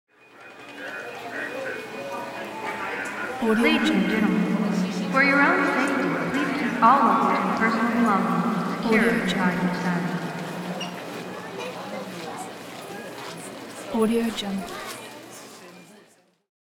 دانلود افکت صوتی اطلاعیه فرودگاه با پس‌زمینه صدای ترمینال